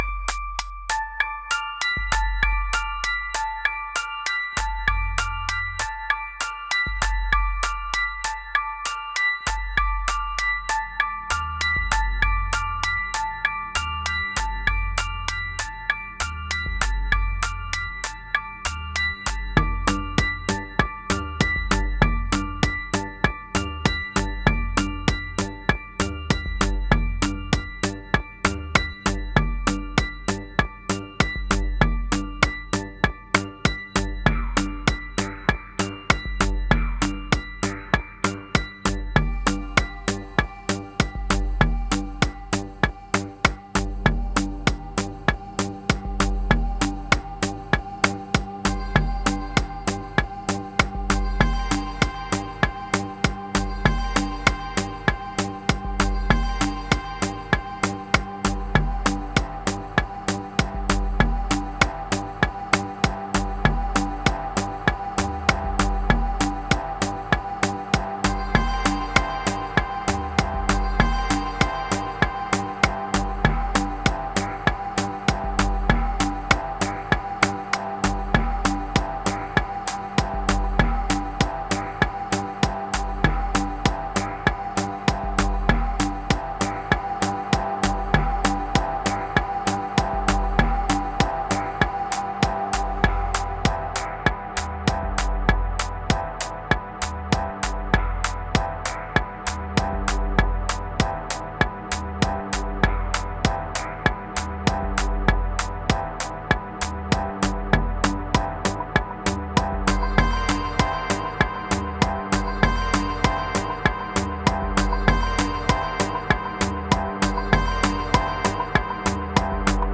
Prepare your subwoofer or your deep headz.